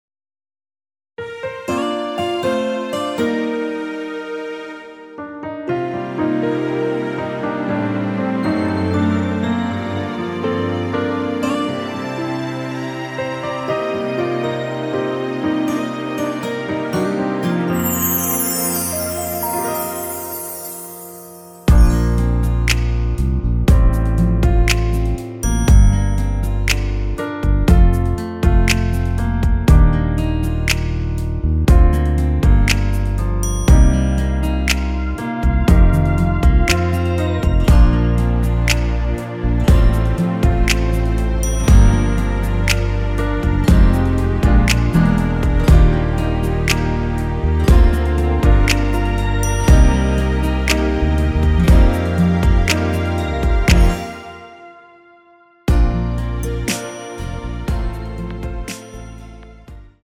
MR입니다.
Bb
앞부분30초, 뒷부분30초씩 편집해서 올려 드리고 있습니다.